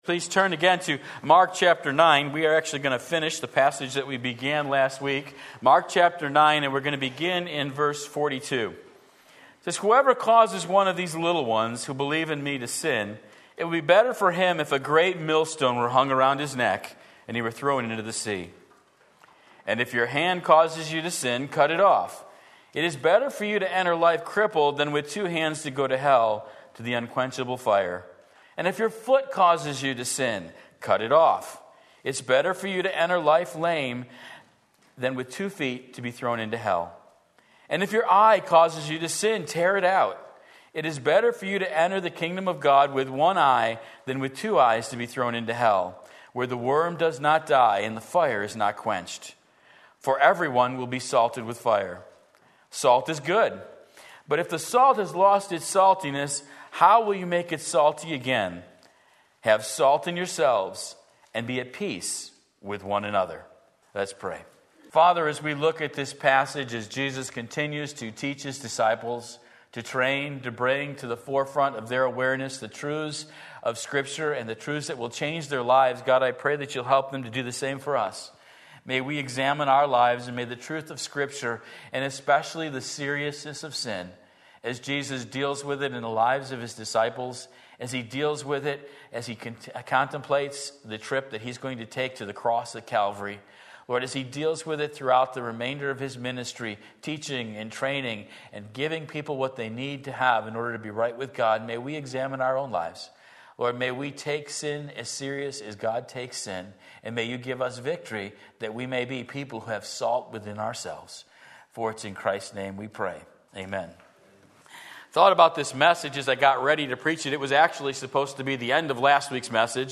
Sermon Link
The Seriousness of Sin Mark 9:42-50 Sunday Morning Service